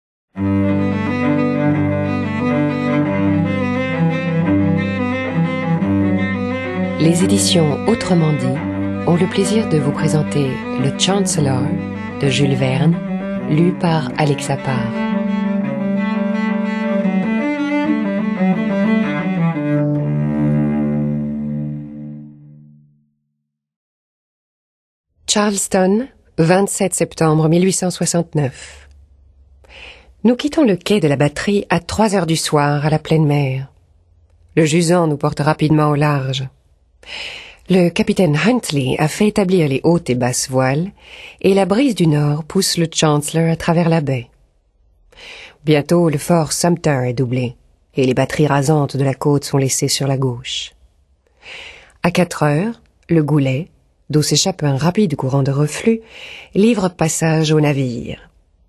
Diffusion distribution ebook et livre audio - Catalogue livres numériques
lit de sa voix riche en nuances ce récit avec une grande virtuosité.